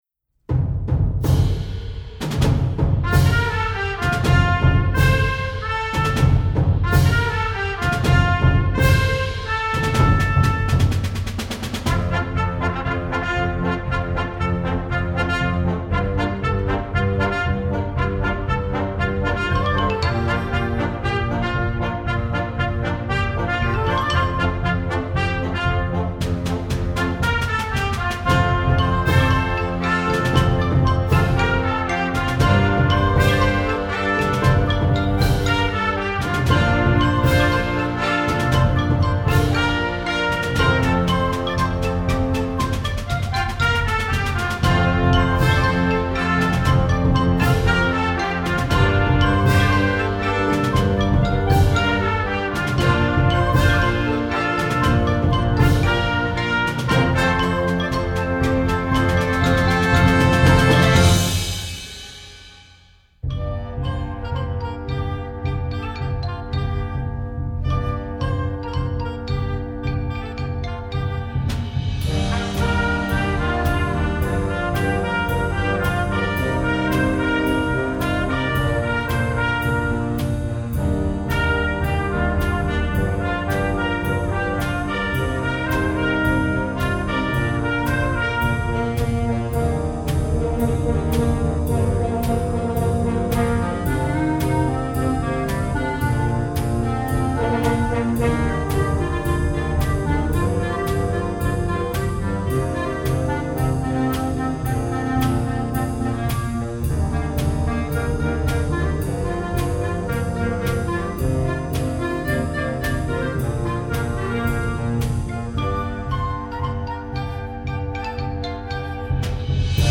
Filmmusik für Jugendblasorchester
Besetzung: Blasorchester